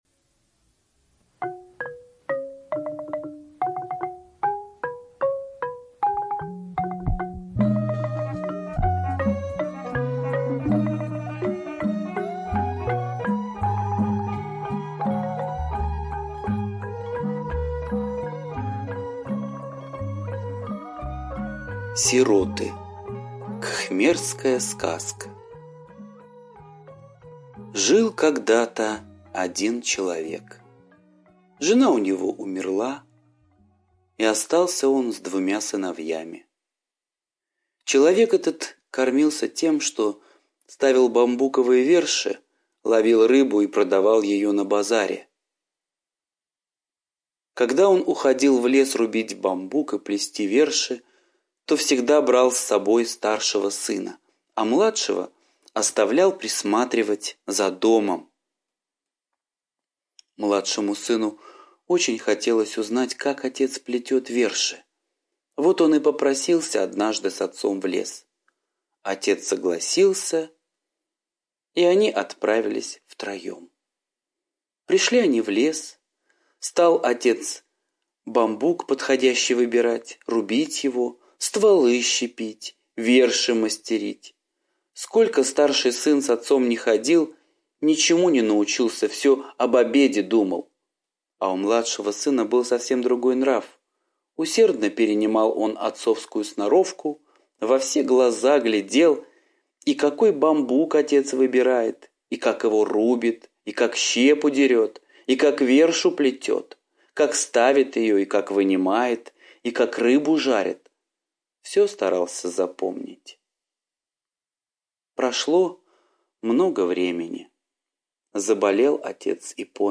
Сироты - восточная аудиосказка - слушать онлайн